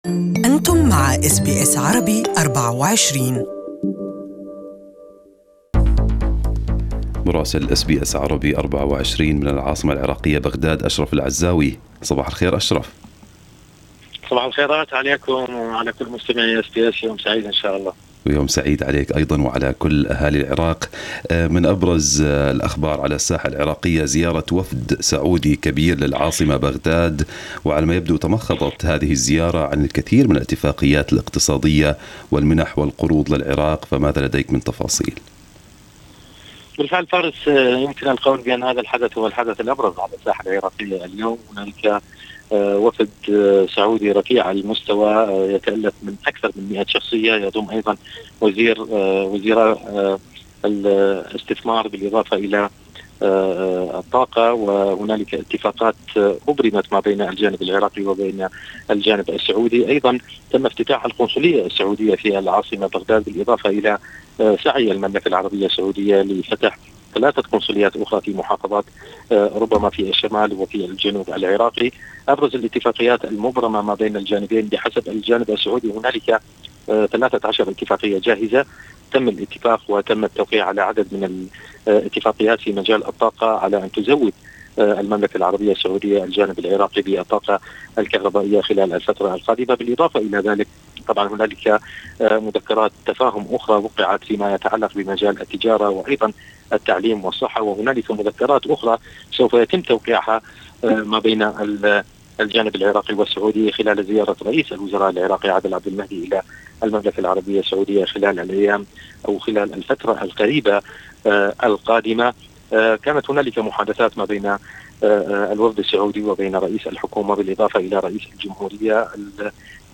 Our Correspondent in Iraq has the details